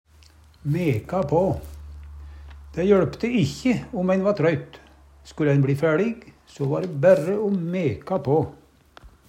DIALEKTORD PÅ NORMERT NORSK meka på drive på jamnt og trutt Eksempel på bruk Dæ jøLpte ikkje om ein va trøytt, skulle ein bLi fæLig, so va dæ bærre o meka på.